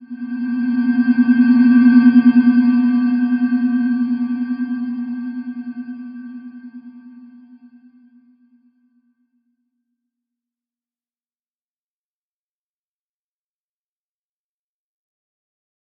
Slow-Distant-Chime-B3-f.wav